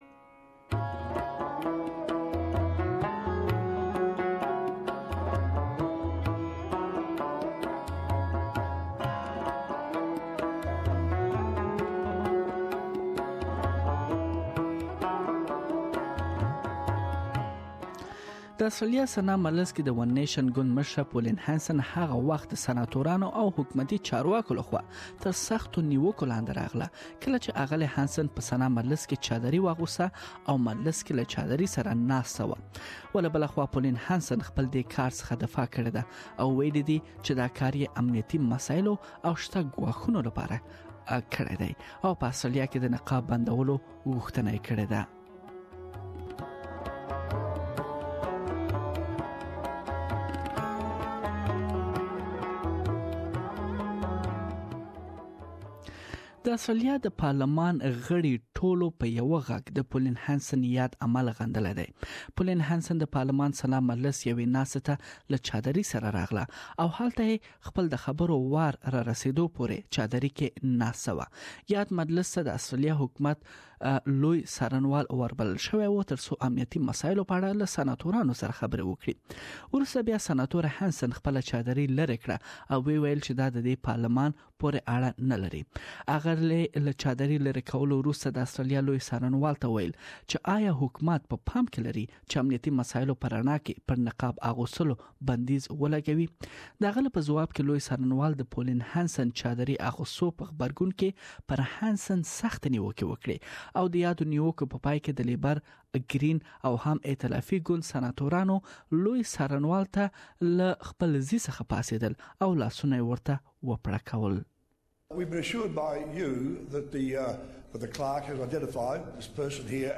The move has been criticised by both sides of politics, but she says she is not at all embarrassed by what she did. Please listen to the full report here.